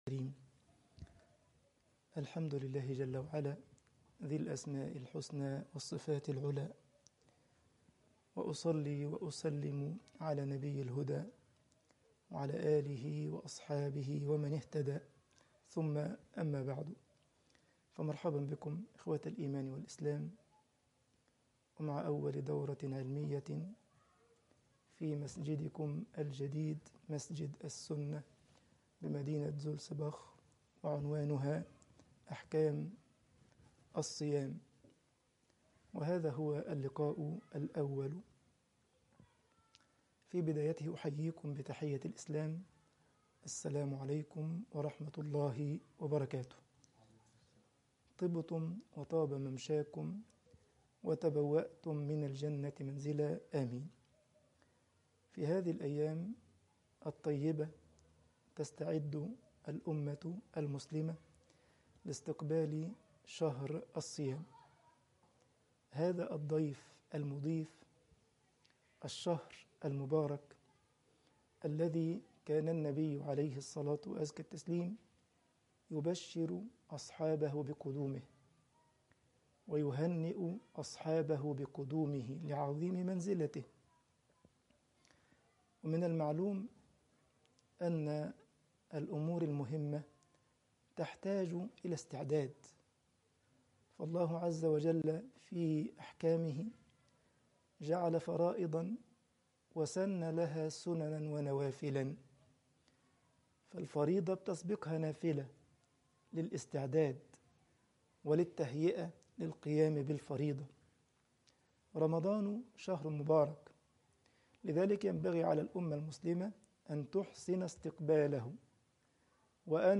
الدورة العلمية رقم 1 أحكام الصيام المحاضرة رقم 1